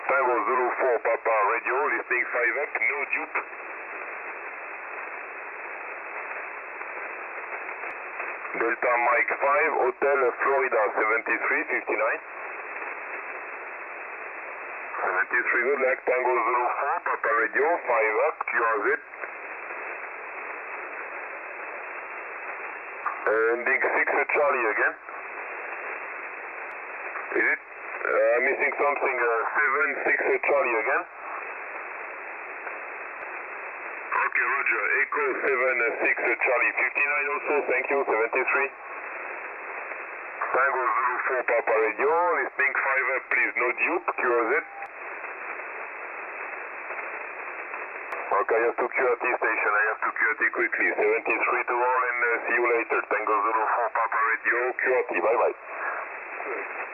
TZ4PR 20SSB